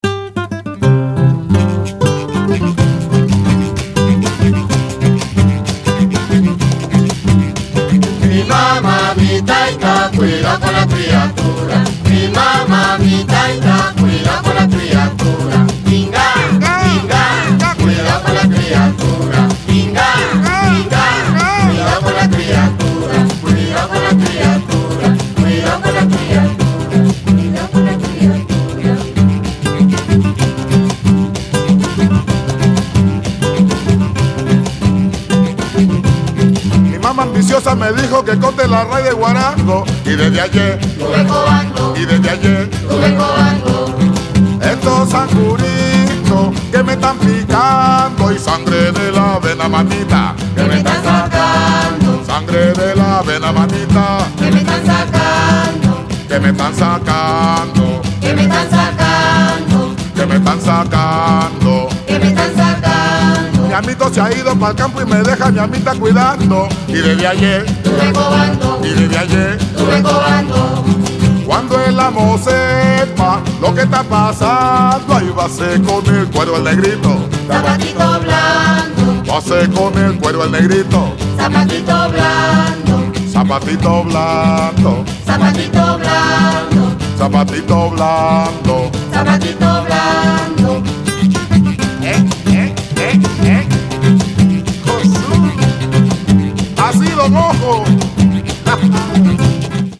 最高！アフロ・ペルー音楽の金字塔！
1枚目は彼の詩人としての本領を発揮したリーディングを中心に、ギターとカホンを加えた渋くも味わい深い内容！